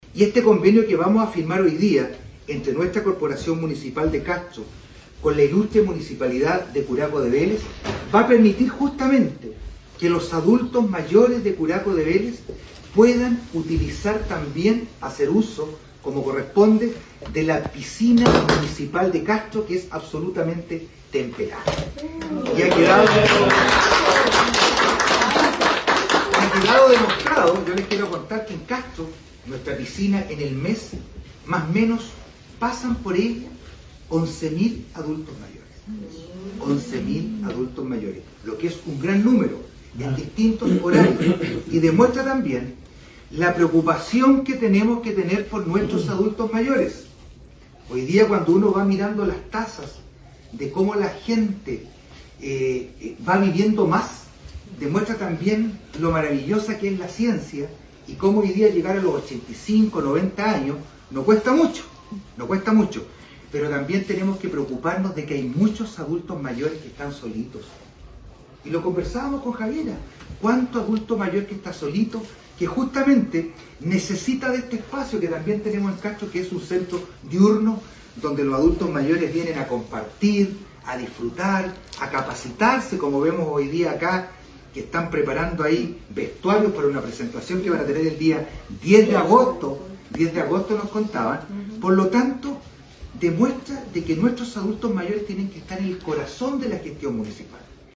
ALCALDE-VERA-CONVENIO-CON-CURACO-DE-VELEZ.mp3